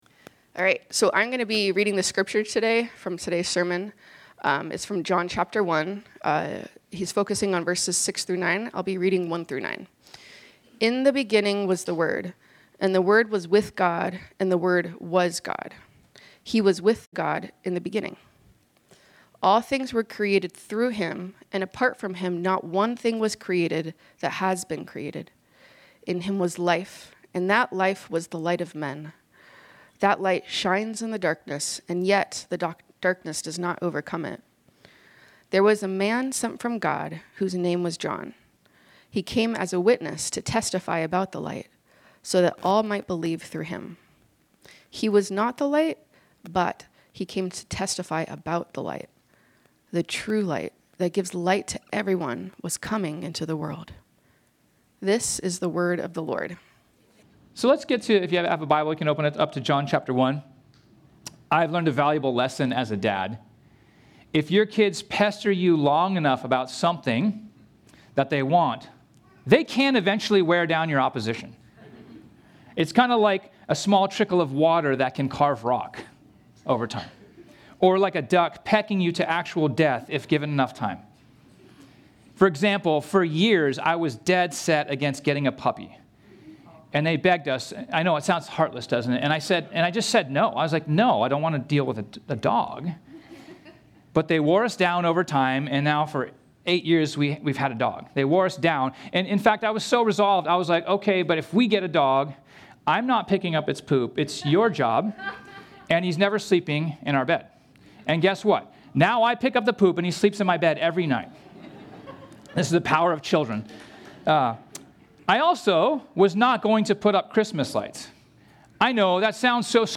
This sermon was originally preached on Sunday, December 14, 2025.